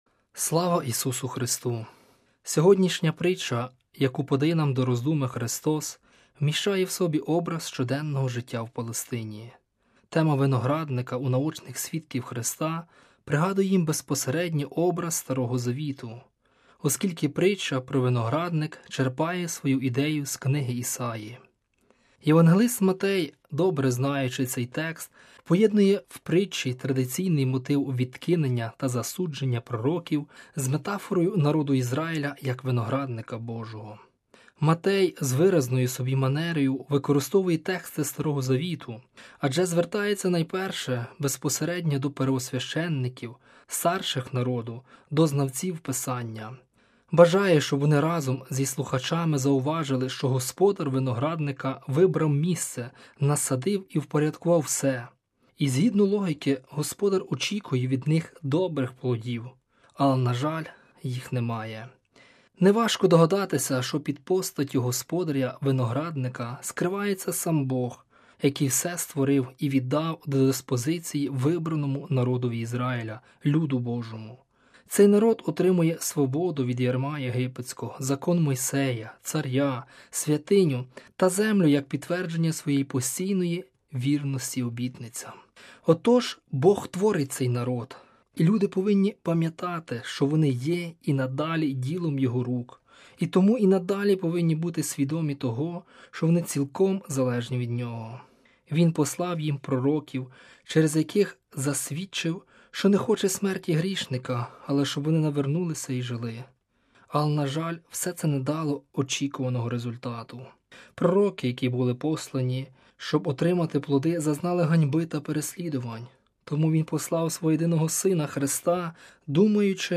Свята Літургія на Ватиканському Радіо 18 вересня 2016 р.
Співали Сестри Служебниці Непорочної Діви Марії.
Пропонуємо вашій увазі звукозапис цієї Святої Літургії: